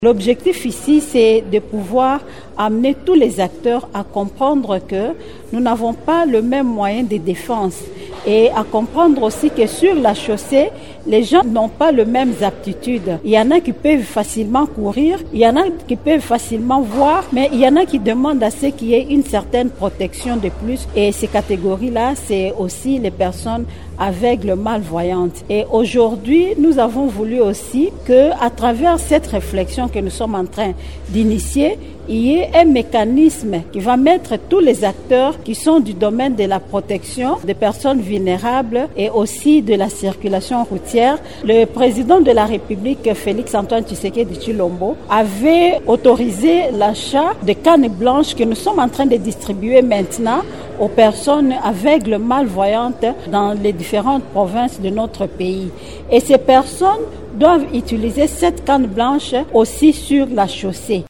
Les des délégués de la PCR et de l’Association des chauffeurs du Congo (ACCO) ont assisté à cette rencontre, organisée à l’occasion de la journée mondiale dite de la Canne blanche.